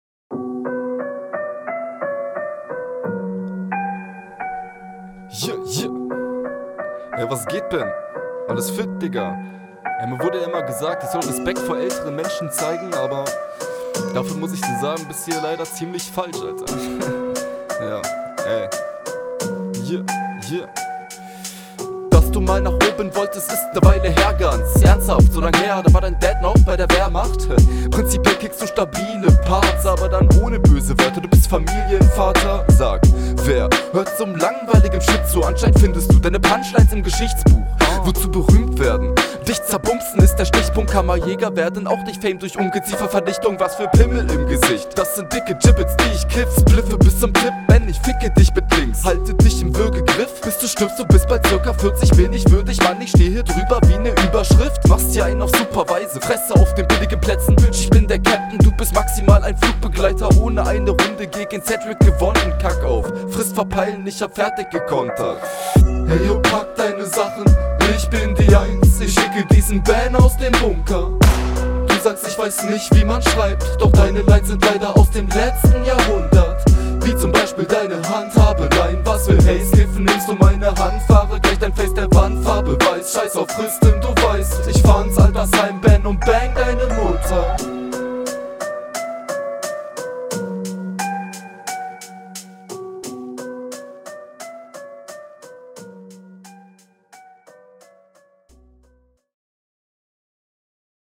Soundtechnisch auf einem ähnlichen Level. Find die Doubles bisschen zu laut.